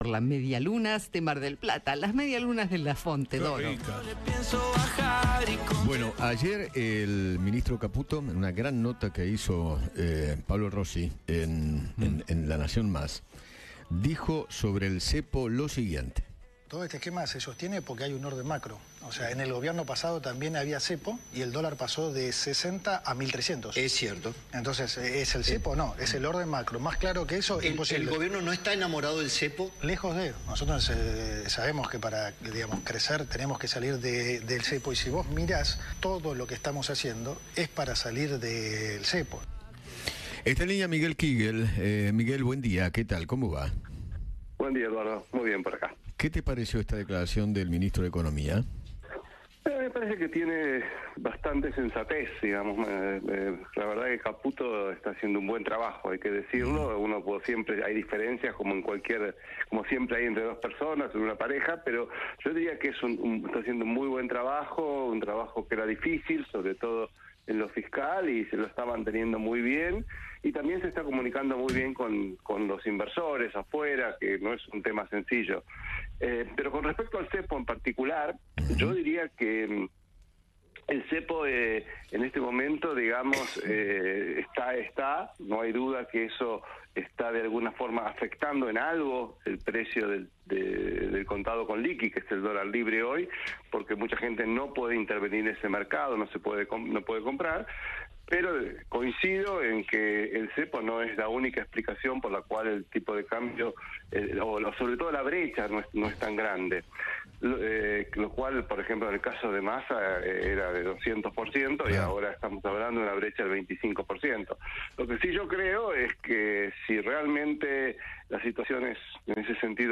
El economista Miguel Kiguel habló con Eduardo Feinmann sobre los dichos del Ministro de Economía, Luis Caputo, acerca del CEPO.